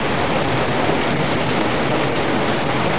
rain2_nl.wav